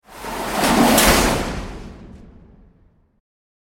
Jail-sliding-door-slam-sound-effect-cinematic-audio.mp3